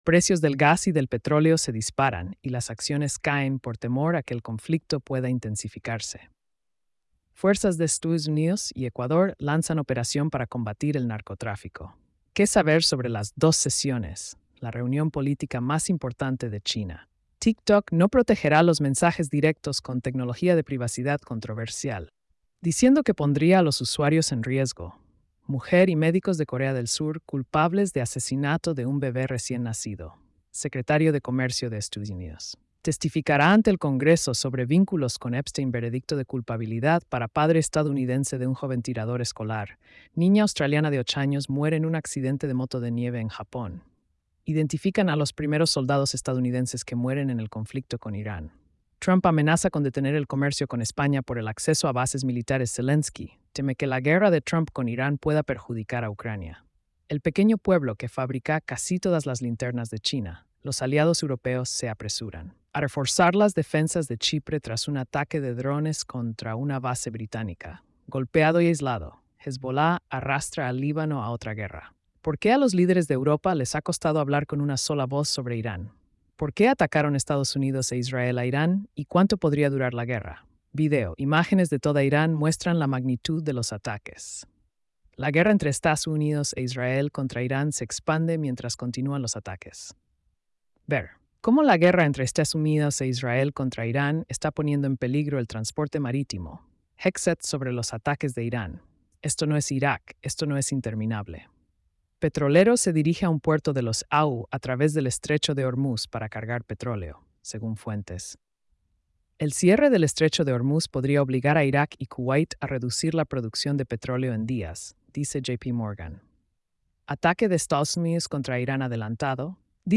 🎧 Resumen de noticias diarias.